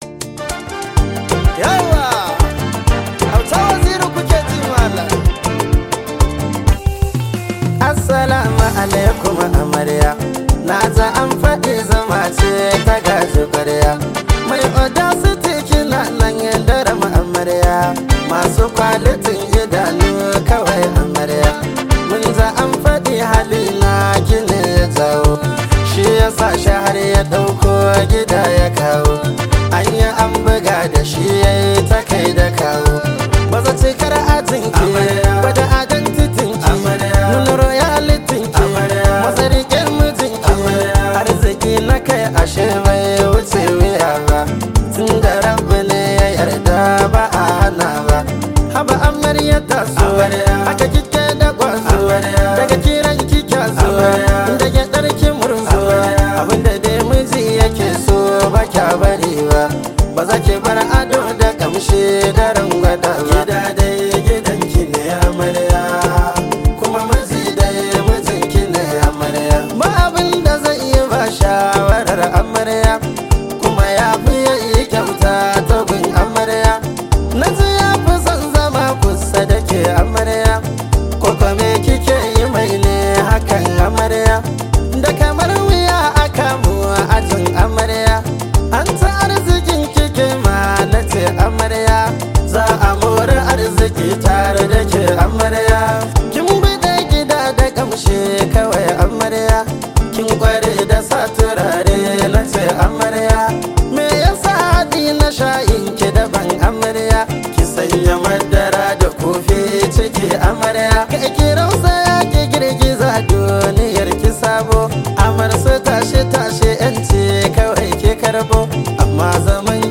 Hausa love song